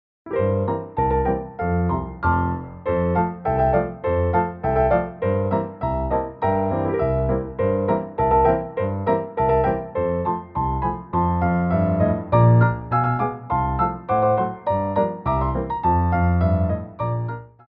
2/4 (8x8)